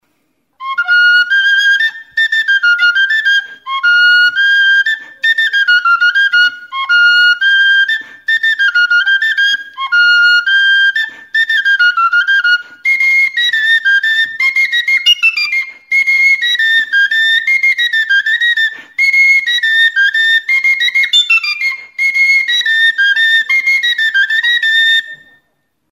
Instrumentos de músicaTXILIBITOA; WHISTLE; FLAUTA
Aerófonos -> Flautas -> Recta (dos manos) + kena
Grabado con este instrumento.
Bi eskuko flauta zuzena da.
Fa tonuan afinaturik dago.